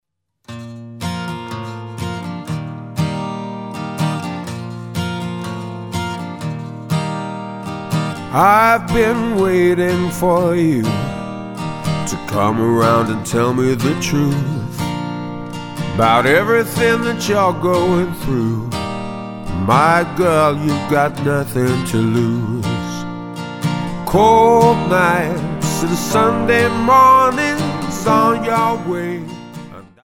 Tonart:Bb Multifile (kein Sofortdownload.
Die besten Playbacks Instrumentals und Karaoke Versionen .